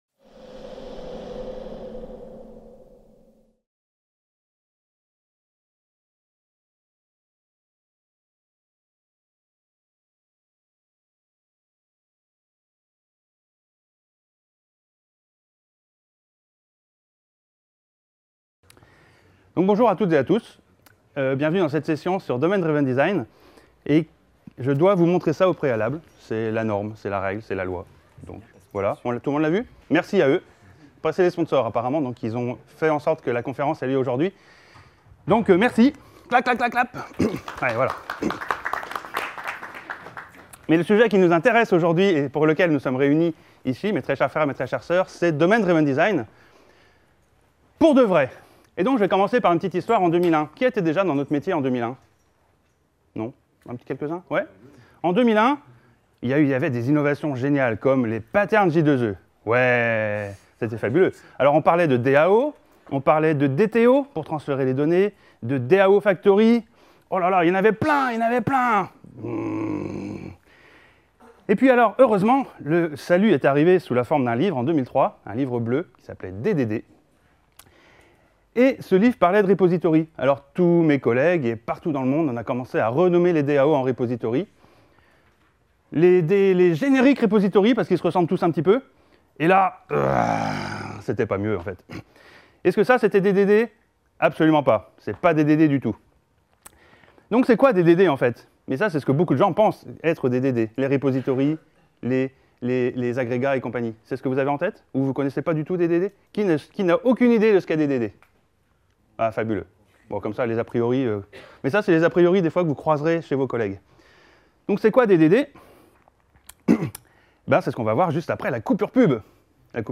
La conférence : DDD est un sujet important mais fréquemment maltraité, de l’obsession malsaine du pattern Repository jusqu’aux auto-proclamés ”frameworks DDD”, sans parler des articles de blogs trompeurs. Pourtant l’essentiel est ailleurs, et l’objet de ce talk de faire le point sur ce qu’est vraiment DDD.